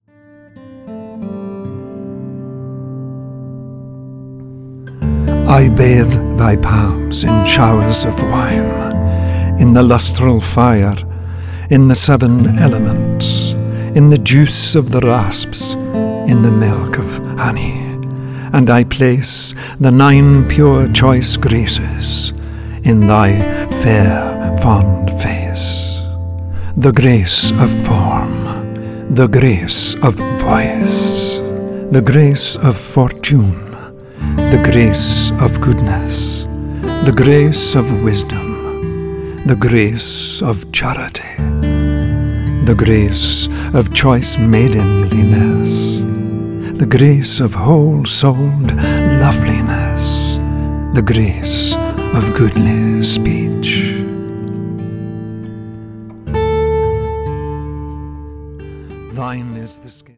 The words are an ancient vocal charm